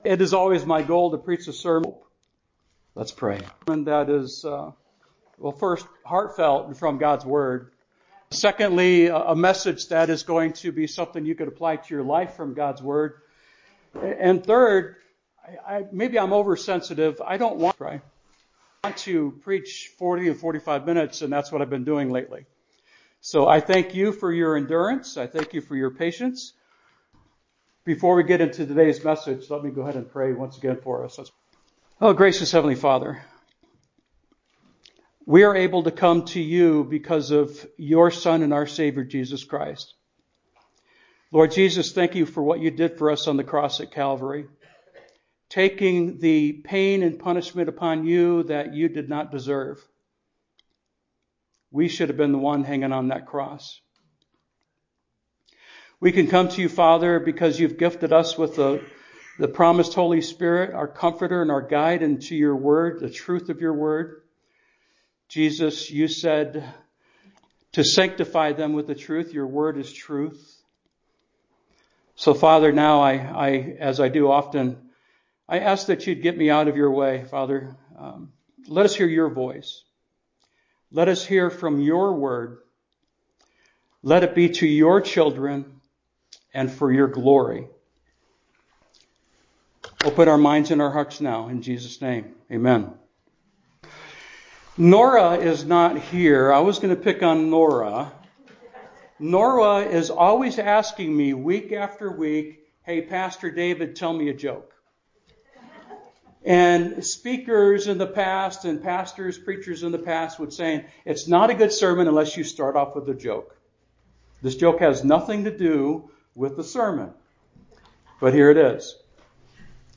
Sermon Title: “Are You Contagious?”